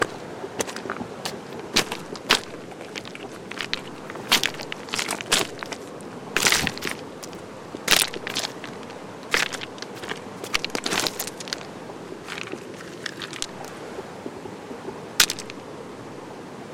鸟水风动
附上一些来自大坝的现场录音，包括河水流动的声音、鸟儿、沿着土路慢跑的人。 由于我经常带着我的狗来大坝，她的狗牌的声音有时也会出现在声景中。 由于这是一个城市环境，飞机的声音有时会出现。 还有交通的声音，我经常发现它与流水的声音，或海浪与岸边的声音相似。
标签： 野外录音 鸟类 河流 白噪音 狗的标签 慢跑
声道立体声